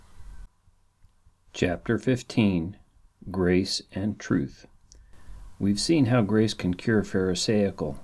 I applied a very gentle noise reduction to the first clip and the attached is a clip from that. Before, after and then before again. You can hear the background noise go up and down.
You can hear the before and after voices are almost identical.